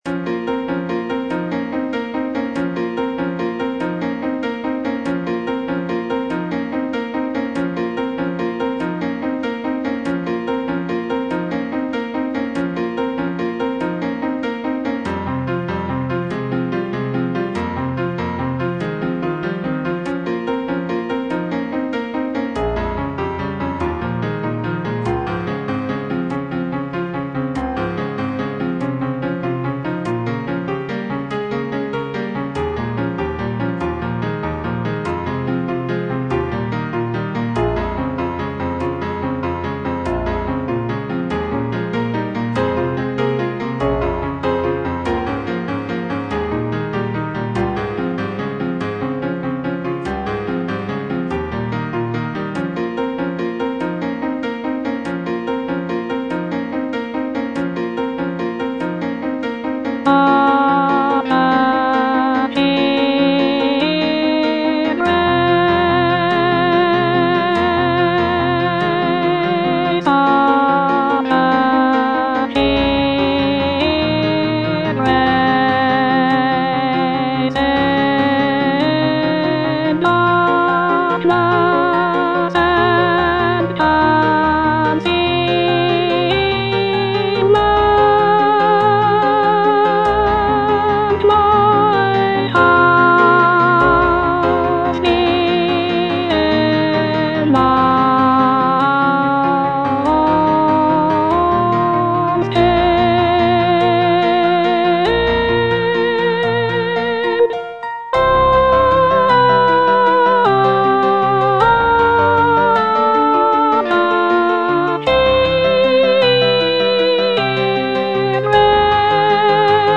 soprano I) (Voice with metronome